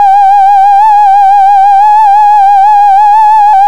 Index of /90_sSampleCDs/USB Soundscan vol.02 - Underground Hip Hop [AKAI] 1CD/Partition D/06-MISC
SCREAM SYN-L.wav